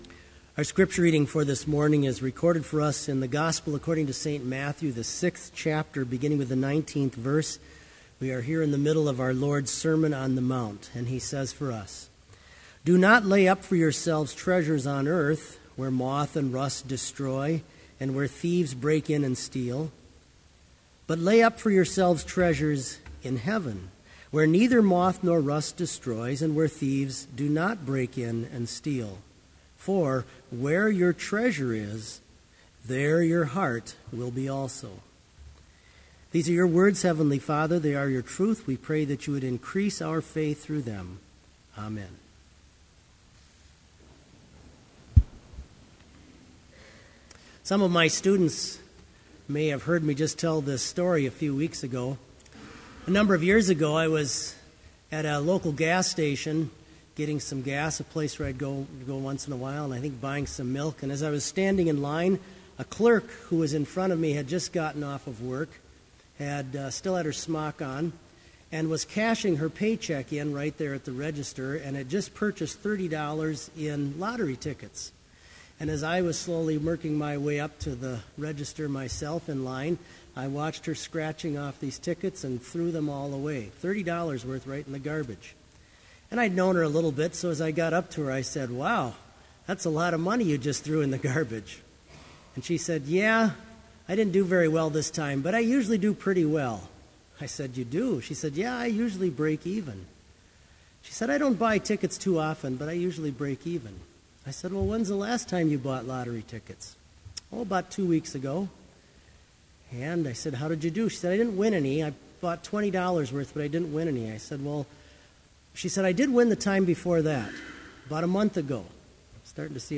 Sermon audio for Summer Chapel - June 5, 2013
Listen Sermon Only Audio file: Sermon Only Order of Service Prelude Hymn 411, vv. 1 & 2, Christians, Come in Sweetest Measure Reading: Matthew 6:19-21 Homily Prayer Hymn 411, vv. 3 & 4, Here our souls…